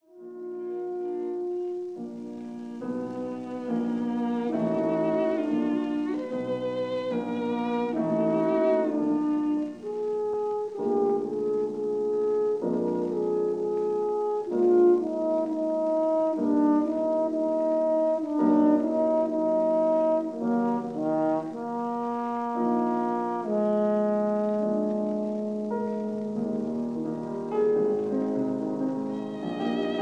This is the Andante.